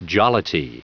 Prononciation du mot jollity en anglais (fichier audio)
Prononciation du mot : jollity